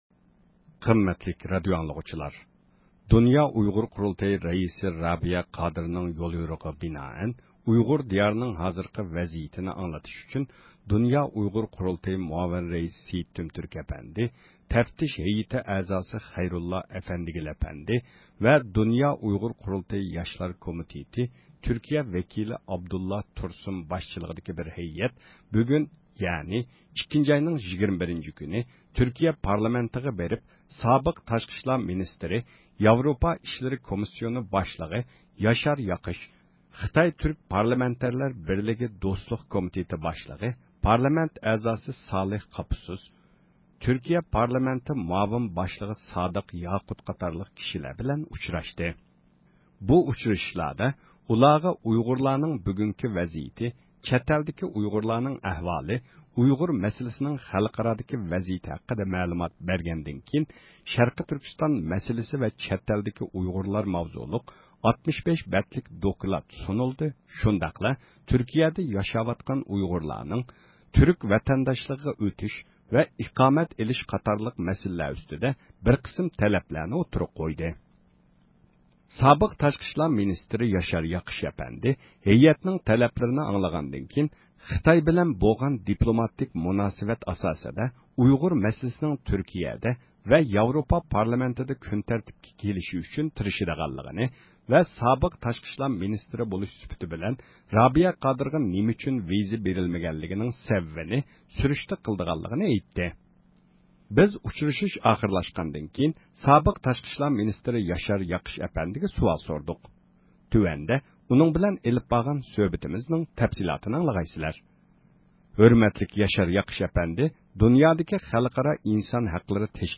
بىز ئۇچرىشىش ئاخىرلاشقاندىن كېيىن سابىق تاشقى ئىشلار مىنىستىرى ياشار ياقىش ئەپەندىگە سۇئال سورىدۇق.